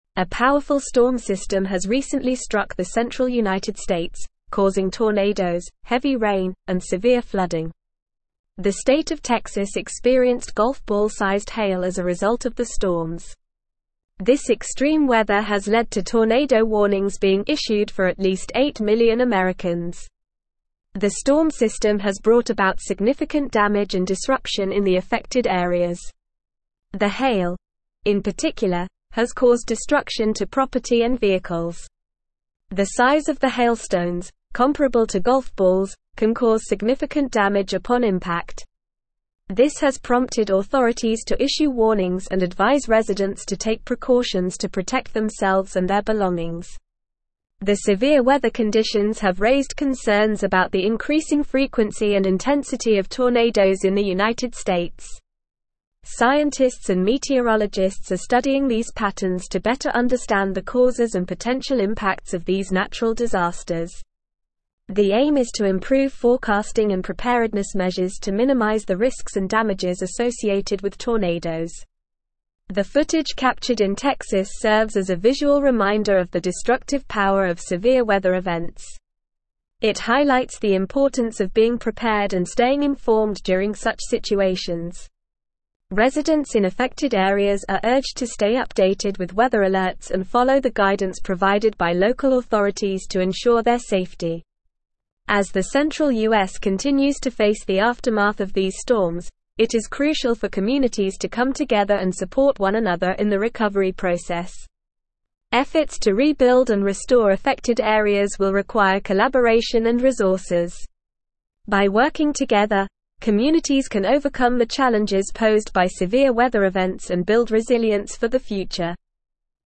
Normal
English-Newsroom-Advanced-NORMAL-Reading-Severe-Storm-System-Causes-Tornadoes-Flooding-and-Hail.mp3